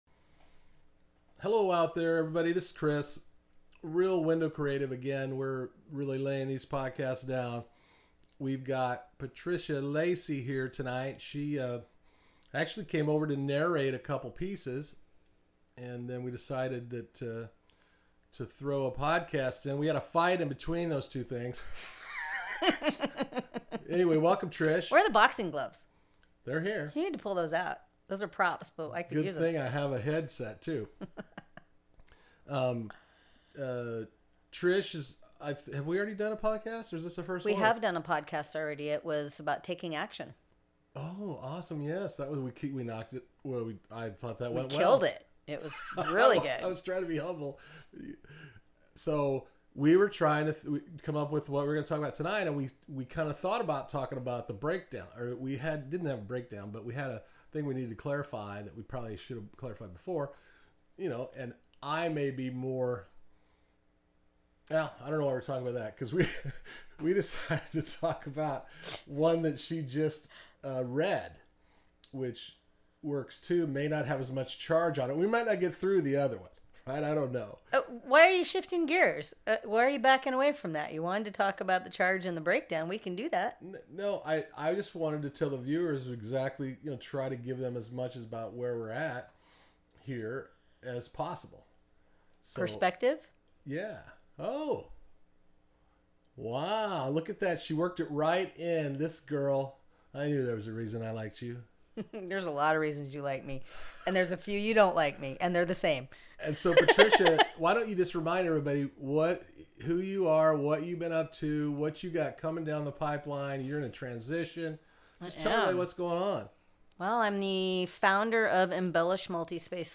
Hear the full interview 50 minutes at Real WIndow Creative on Patreon.